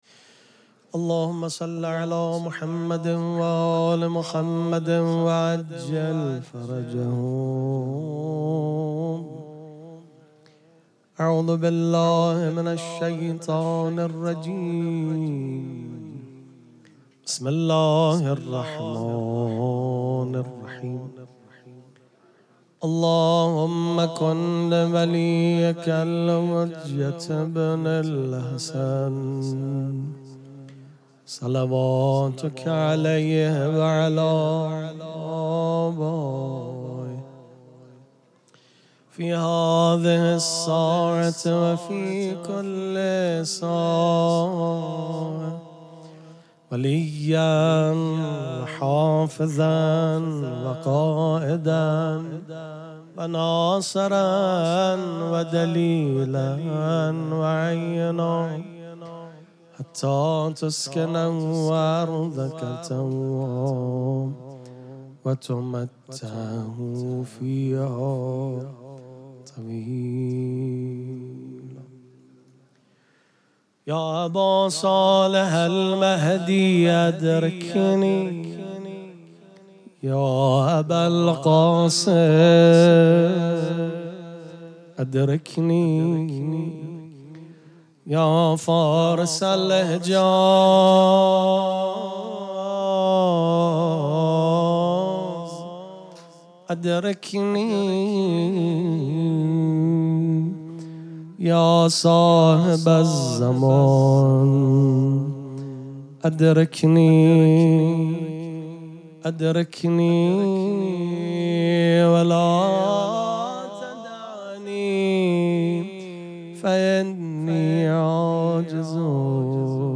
ذکر توسل favorite
شب دوم مراسم جشن ولادت سرداران کربلا
پیش منبر